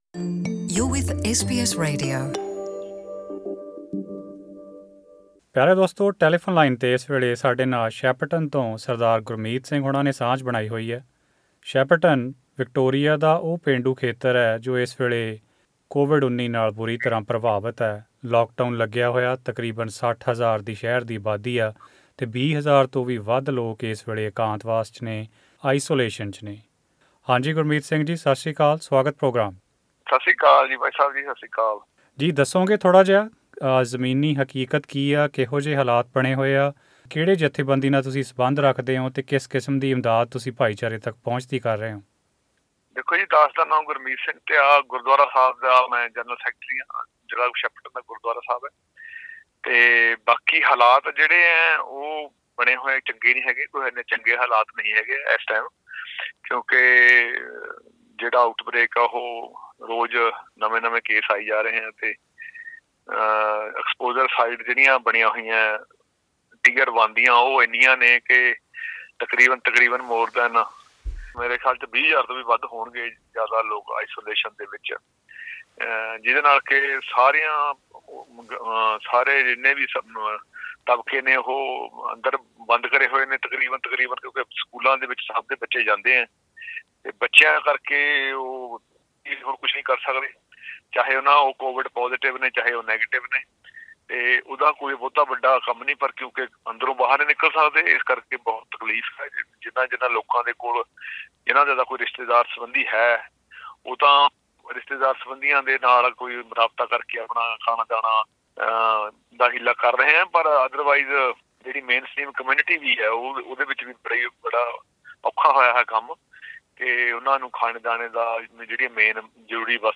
In an interview with SBS Punjabi